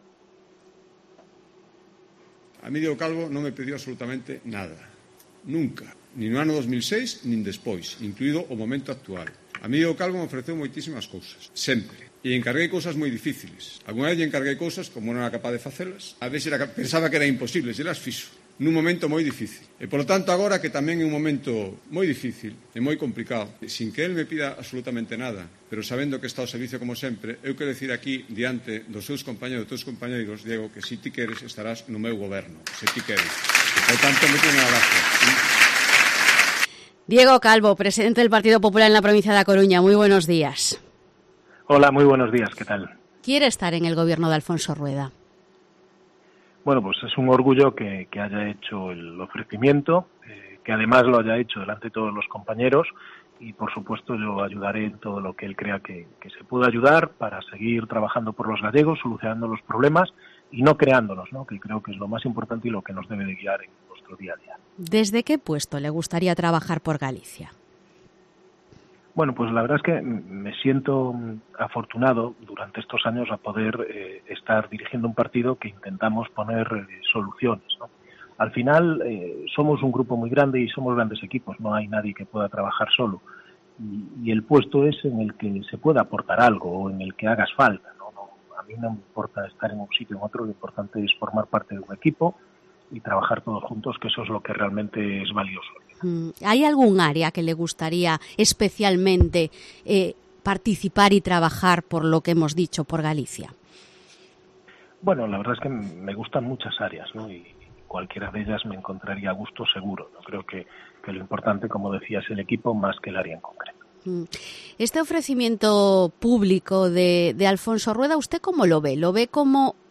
Entrevista a Diego Calvo, presidente provincial del PP en A Coruña